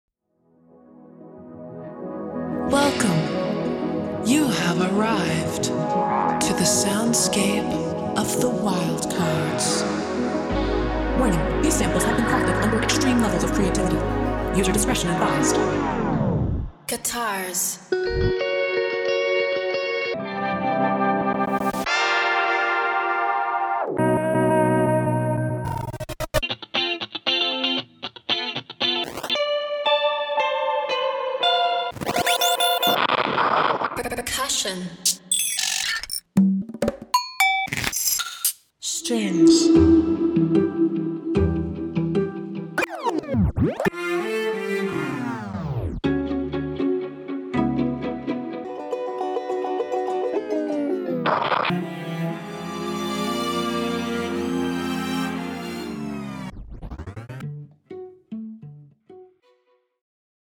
Pop
Production duo
multi-instrumentalists